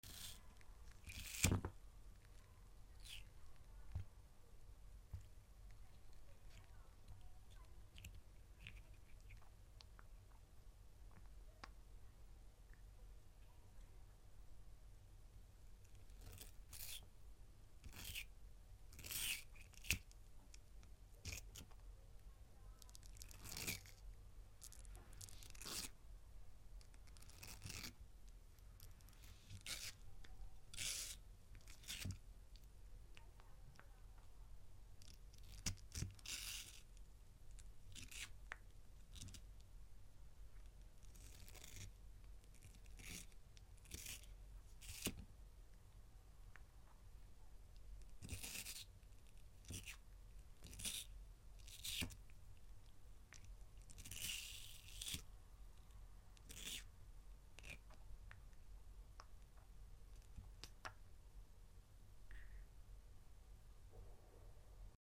The sound of a turtle sound effects free download
The sound of a turtle eating an apple